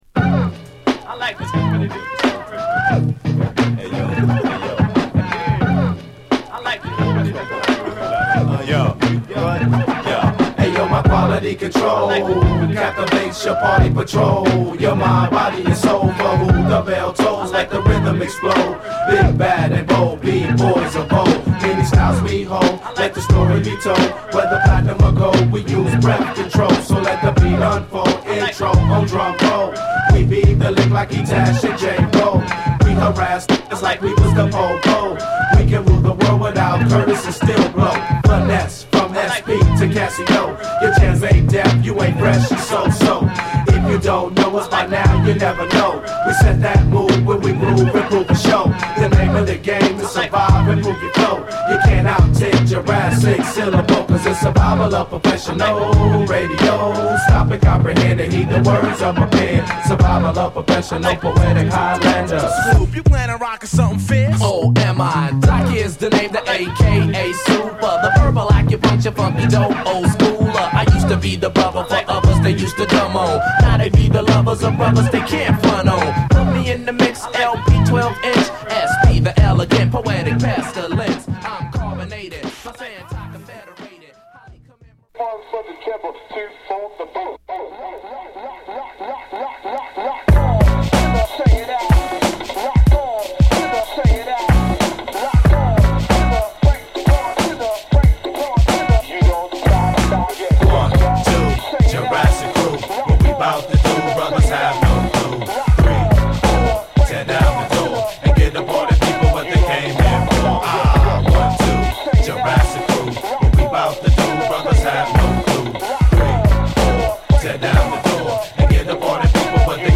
ルーディーでねっとりしたグルーヴがカッコ良い1曲